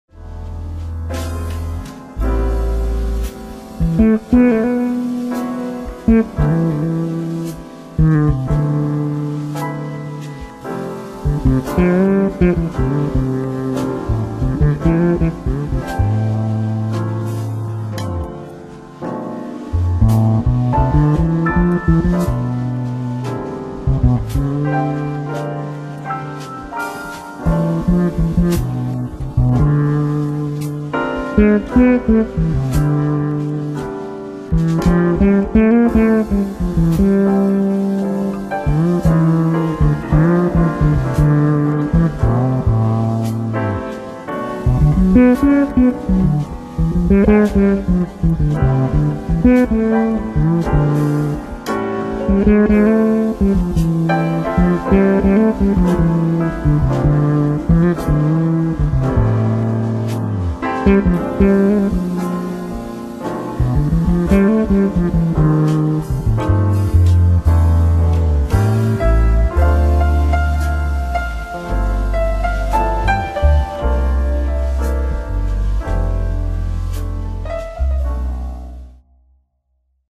Вложения Frtl Bass Solo.mp3 Frtl Bass Solo.mp3 3,1 MB · Просмотры: 347